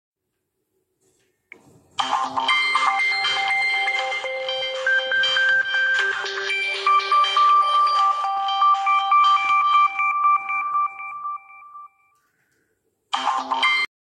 Faint Ringtone Sound Effects Free Download